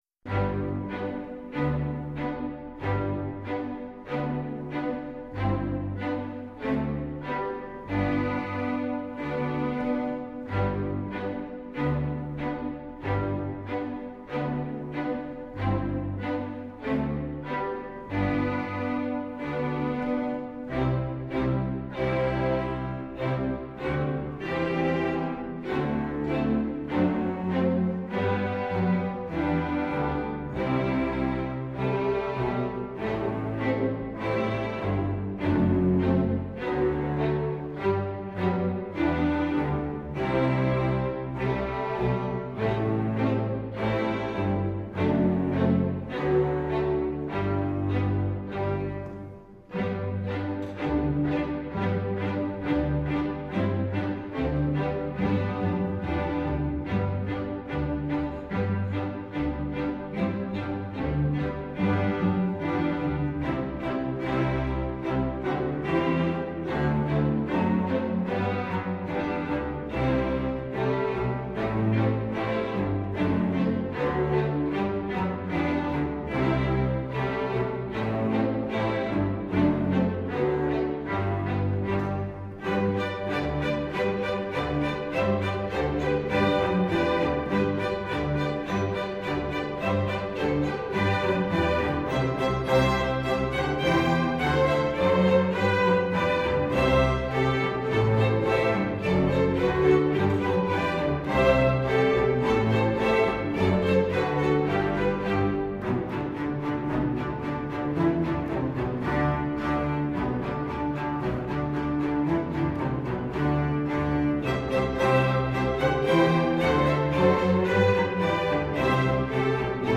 Voicing: String Orchestra S